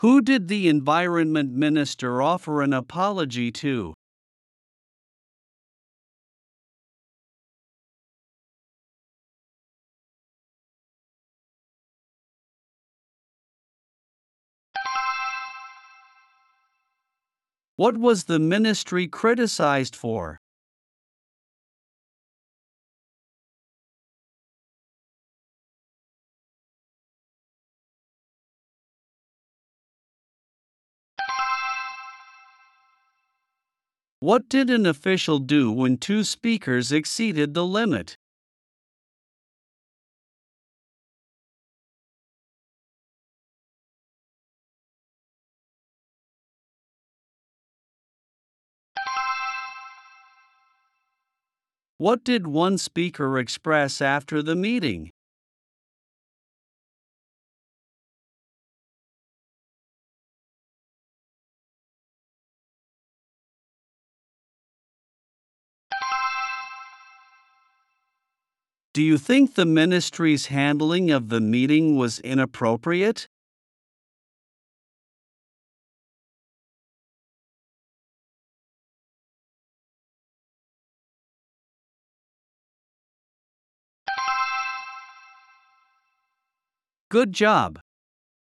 プレイヤーを再生すると英語で5つの質問が1問ずつ流れ、10秒のポーズ（無音部分）があります。
10秒後に流れる電子音が終了の合図です。